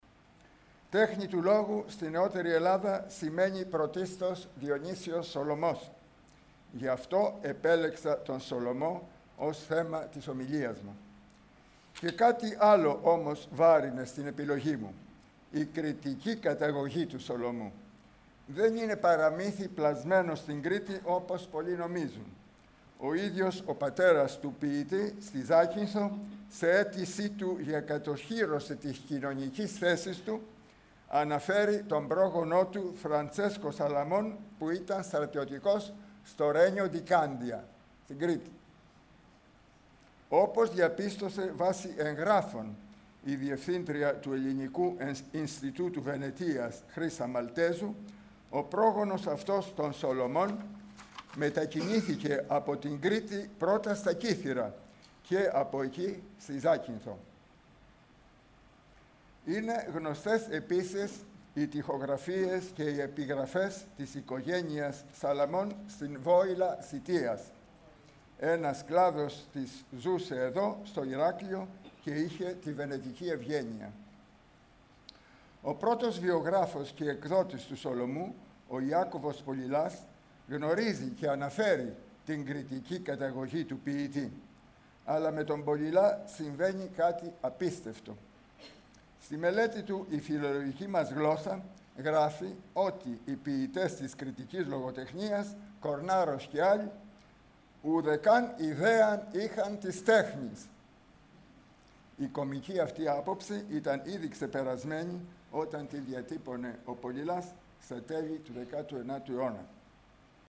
Lecture2